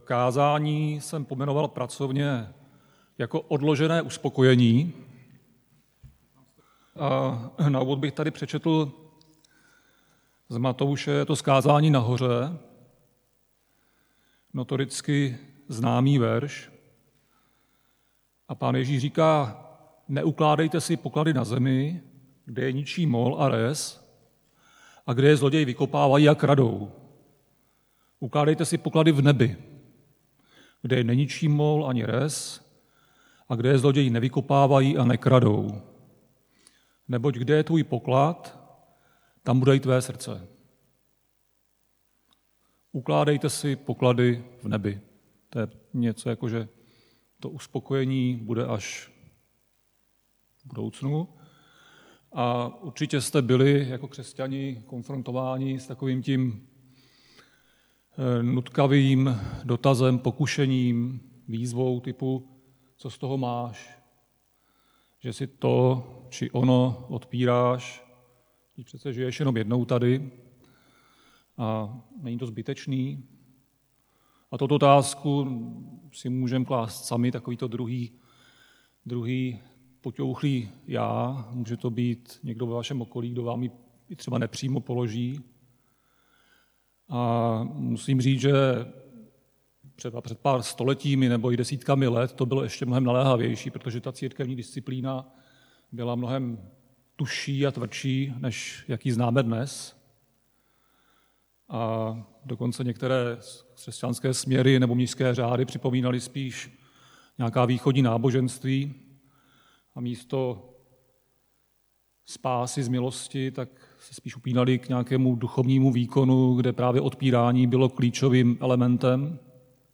Křesťanské společenství Jičín - Kázání 30.7.2023